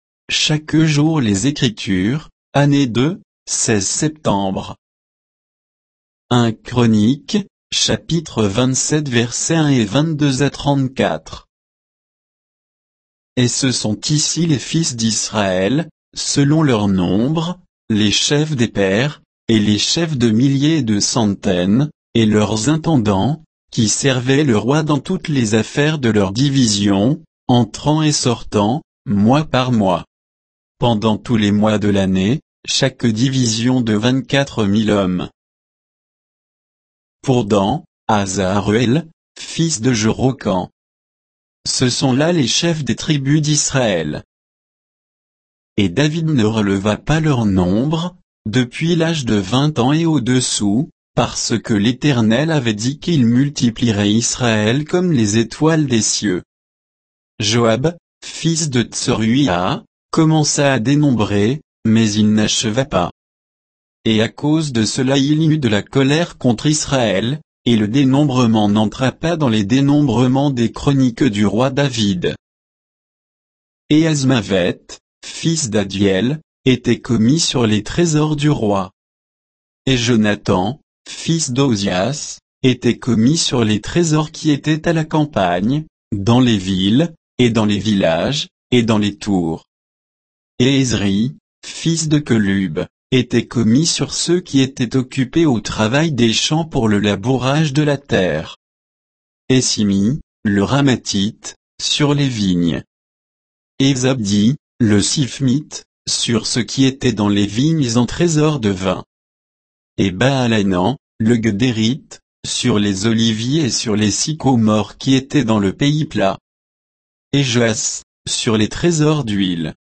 Méditation quoditienne de Chaque jour les Écritures sur 1 Chroniques 27, 1, 22 à 34